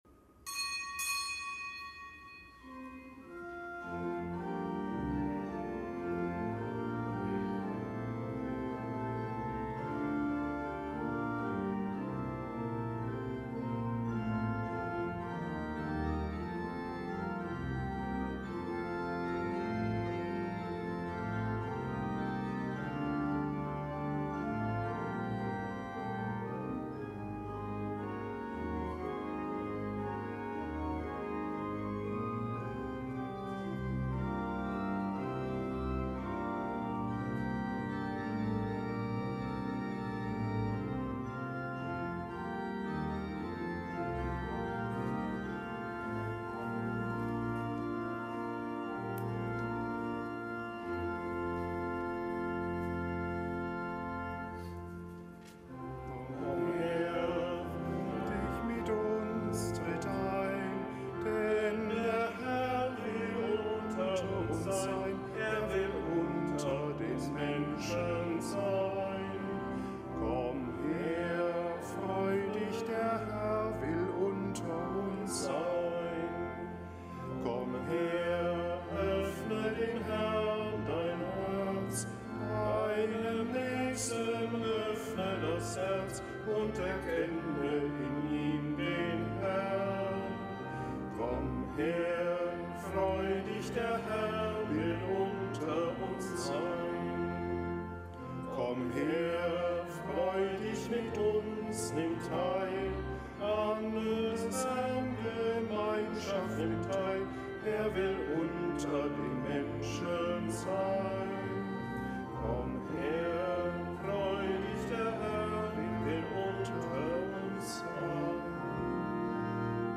Kapitelsmesse am Donnerstag der zehnten Woche im Jahreskreis
Kapitelsmesse aus dem Kölner Dom am Donnerstag der zehnten Woche im Jahreskreis